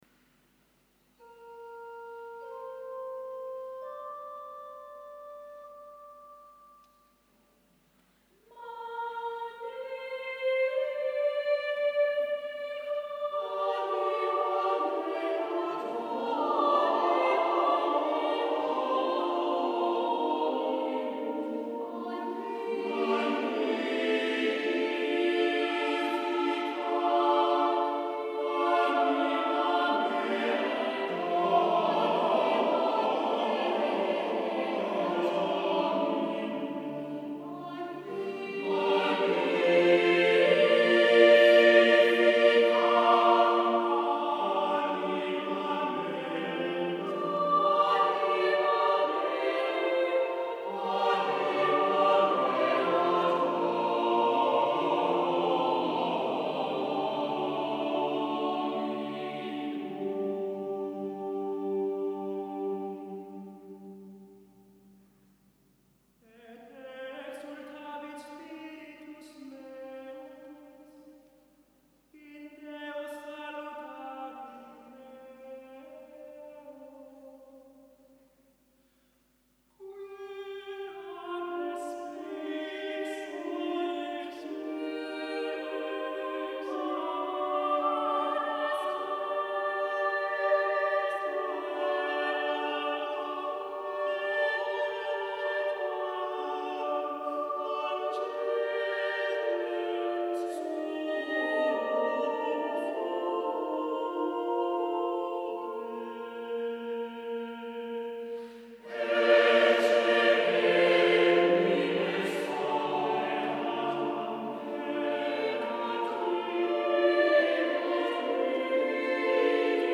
for SATB Chorus, SATB Soloists Duration: 5 minutes